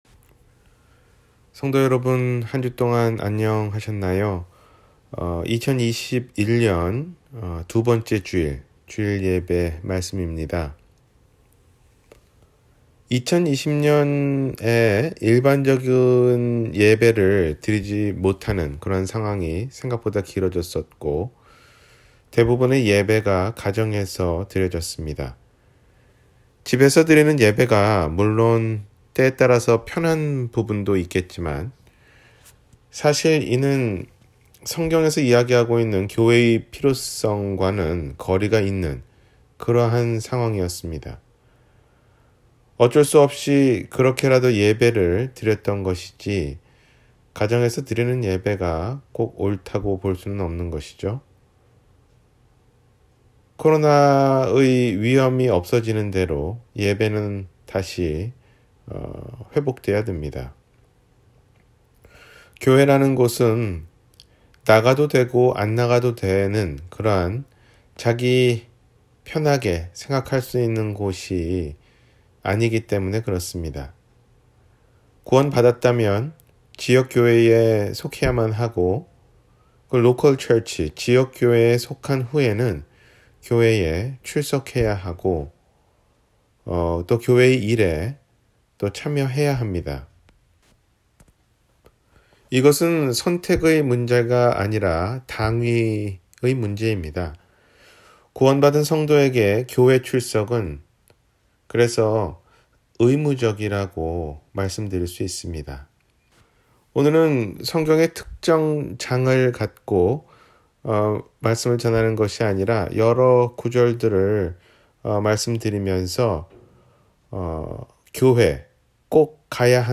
교회, 꼭 가야하나요? – 주일설교